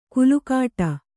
♪ kulukāṭa